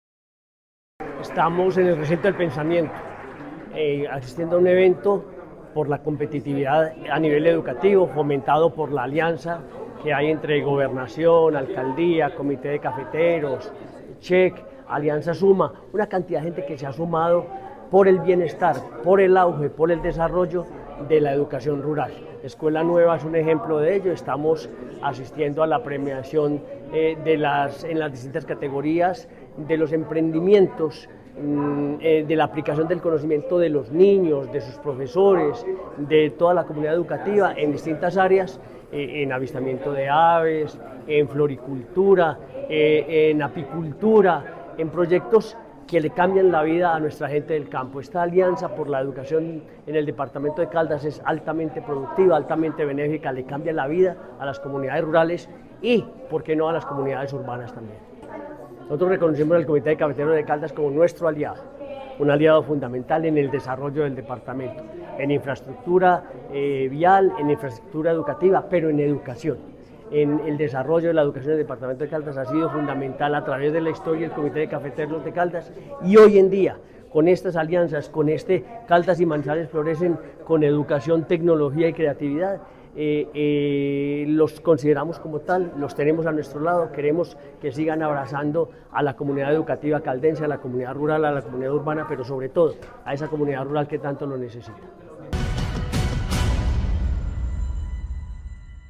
Gobernador de Caldas, Henry Gutiérrez Ángel.